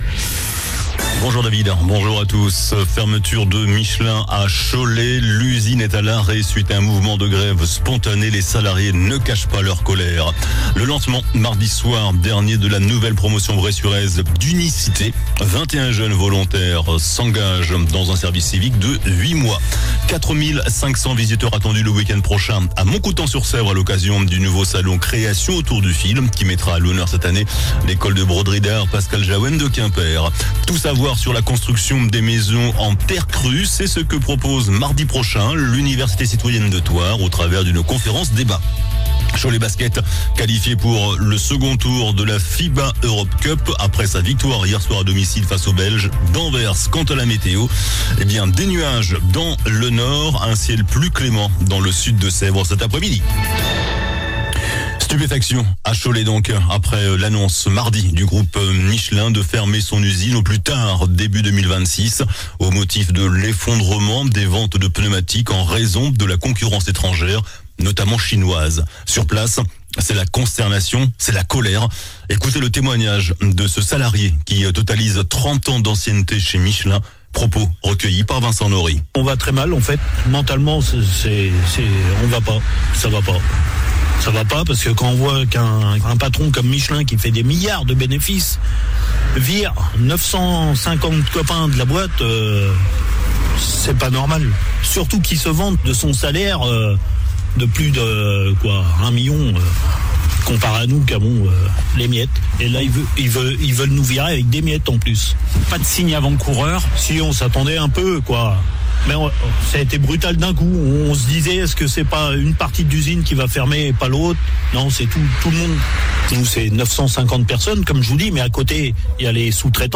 JOURNAL DU JEUDI 07 NOVEMBRE ( MIDI )